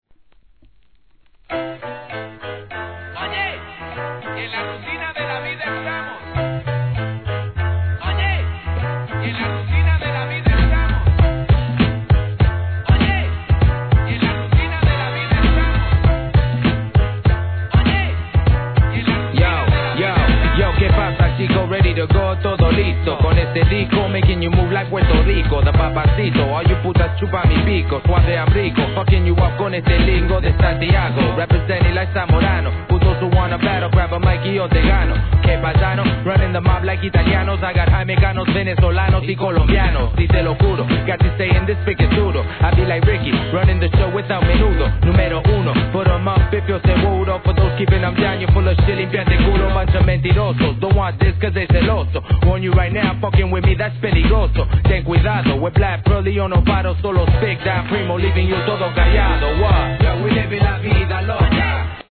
HIP HOP/R&B
ラティーノ色全開!!